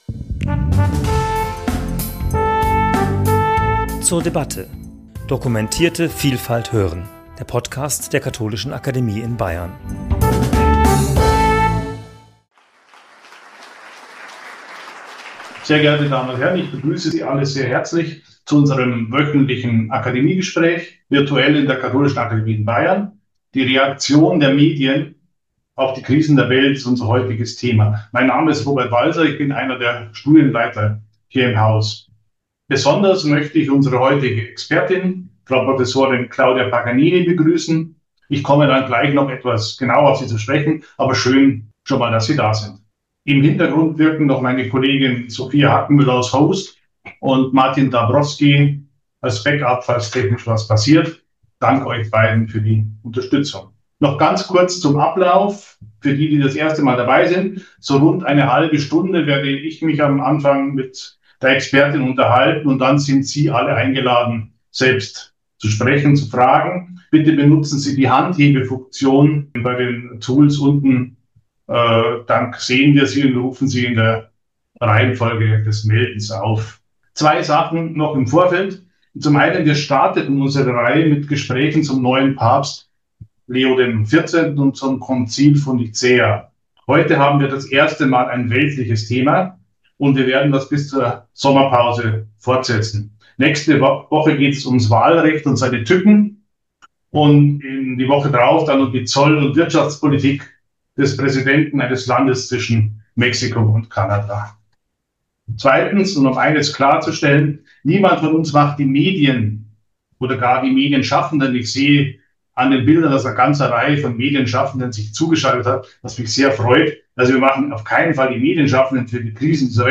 Gespräch zum Thema 'Themen und Sprache - Die Reaktion der Medien auf die Krisen der Welt' ~ zur debatte Podcast